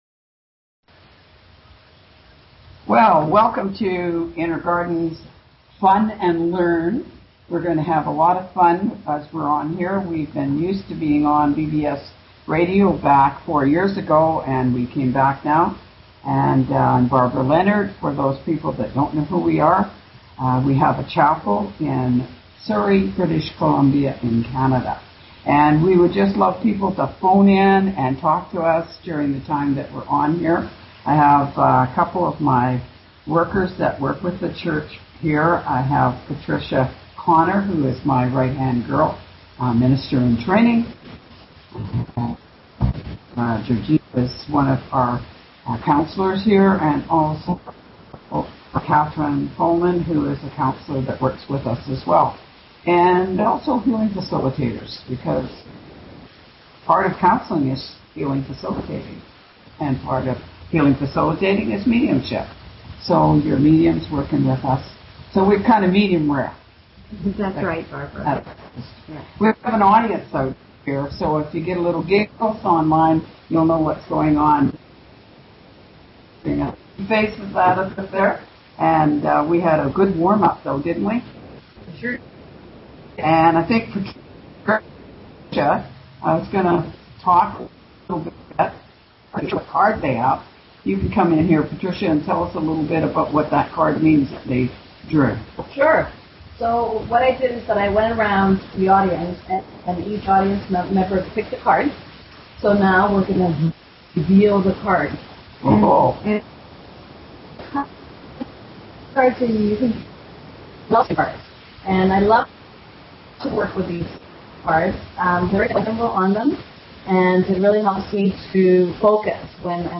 Talk Show Episode, Audio Podcast, Fun_and_Learn_with_Inner_Garden and Courtesy of BBS Radio on , show guests , about , categorized as